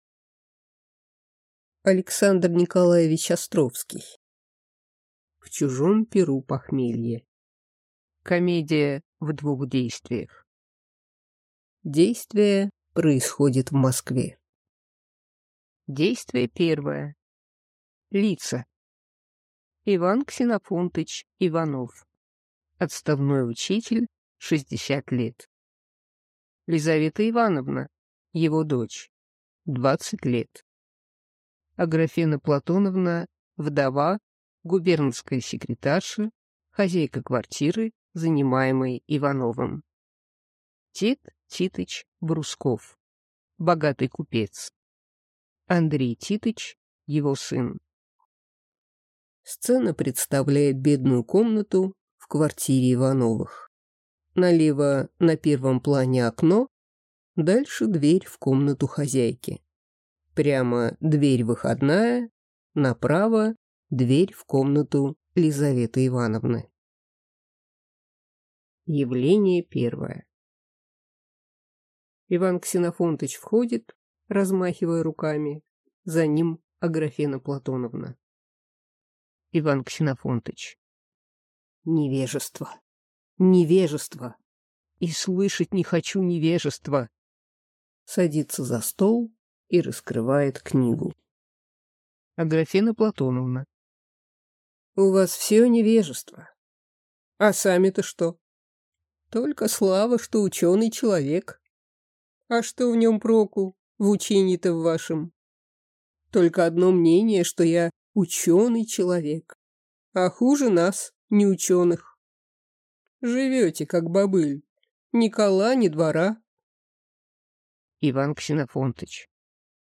Аудиокнига В чужом пиру похмелье | Библиотека аудиокниг